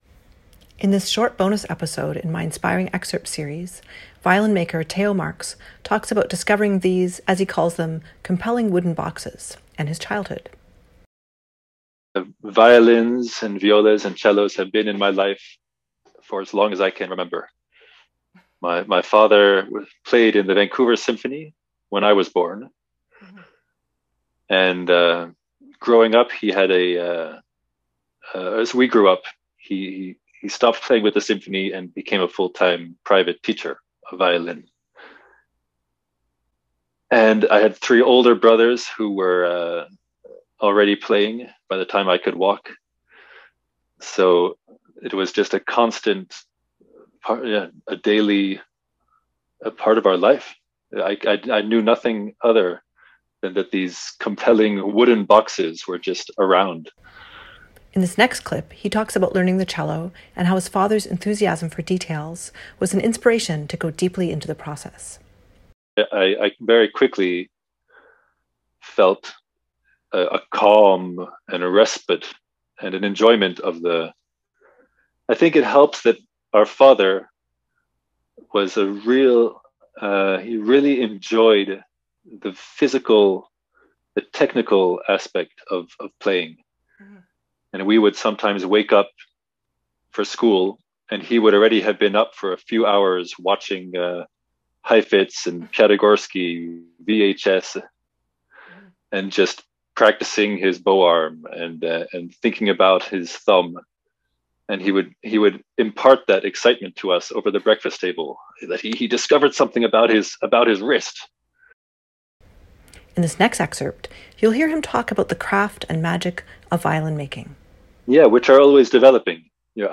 Conversations with Musicians